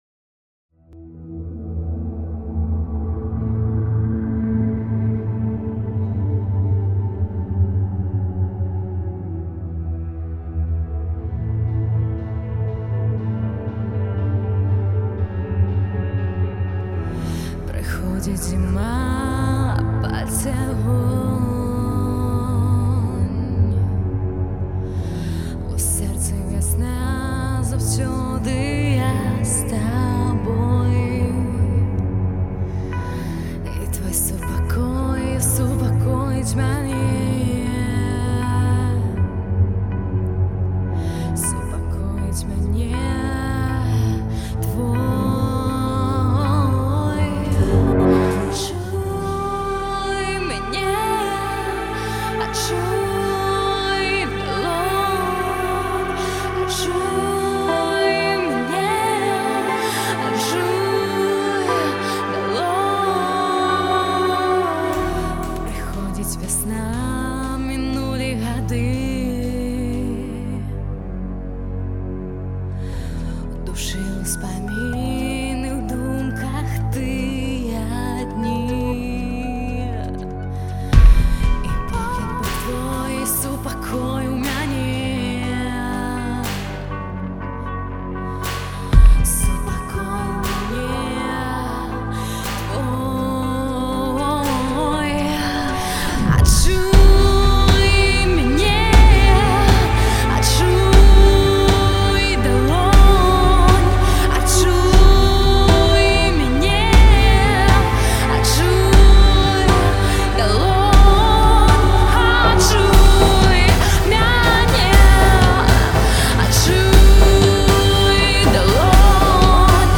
электронны поп-рок праект